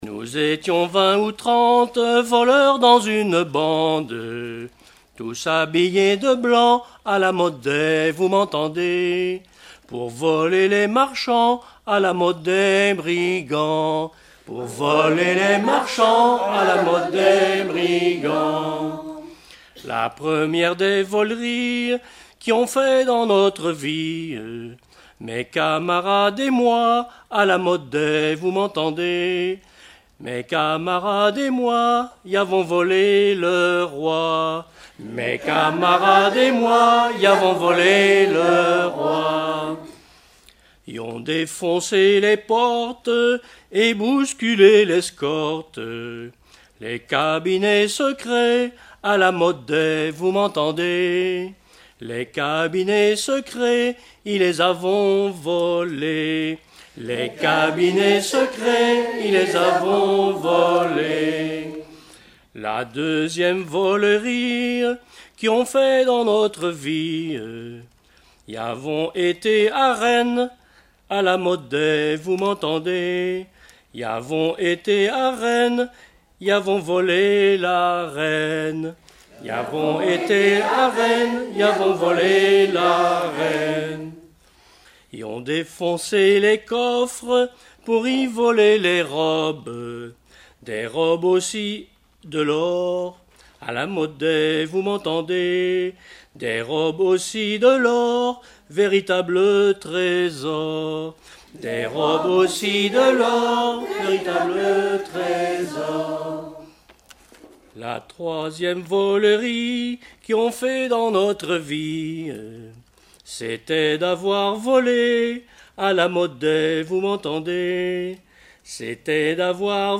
Genre laisse
Chansons traditionnelles et populaires
Pièce musicale inédite